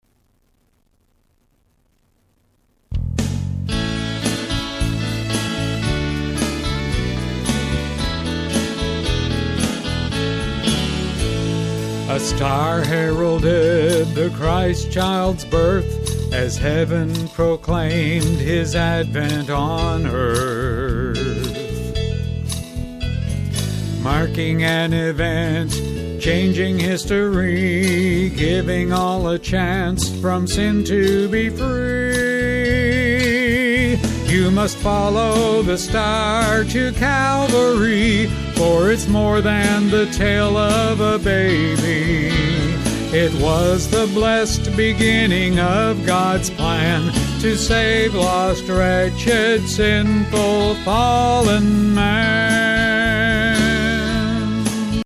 Key of A